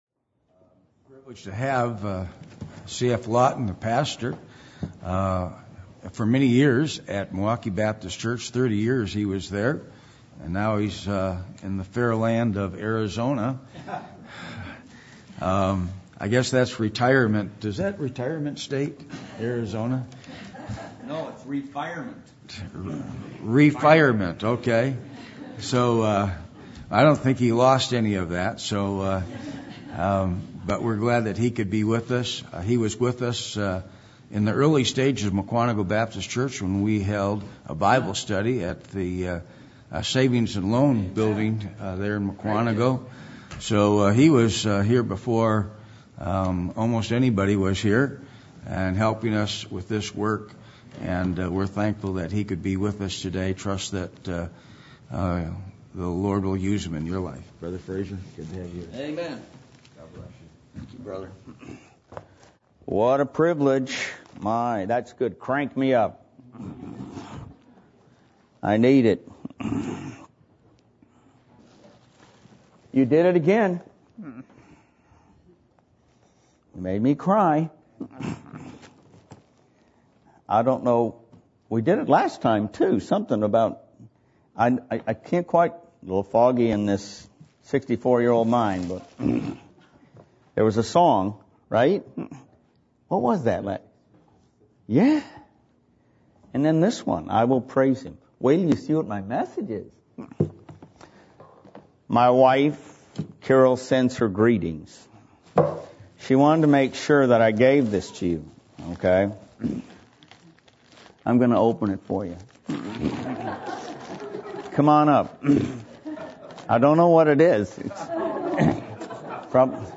Passage: Isaiah 6:1-8 Service Type: Adult Sunday School %todo_render% « Who Are You Trying To Please?